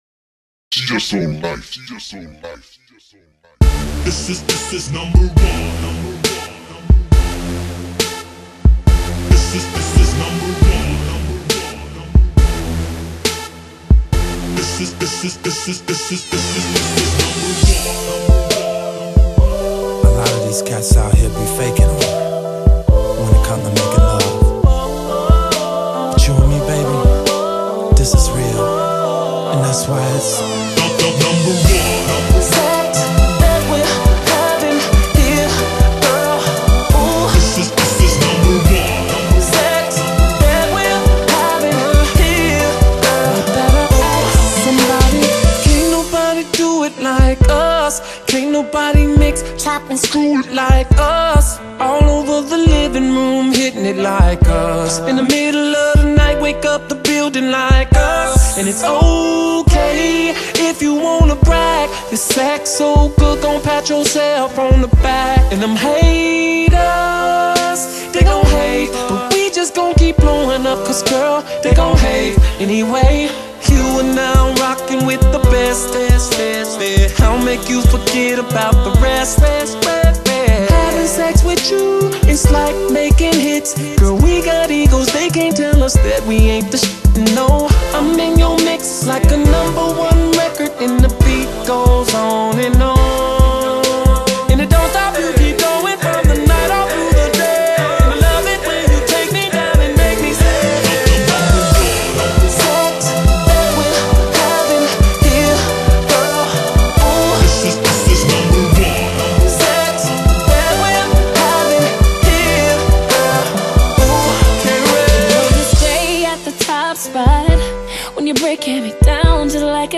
• [해외 / REMIX.]
사운드 정리도 안하고
용량 줄이려다 보니까 음질이 매우 구리네요 ^^;;;
한 1년전쯤 만들어 놓고 방치해 둔 터라 좀 촌스럽기도 하고 ㅎㅎ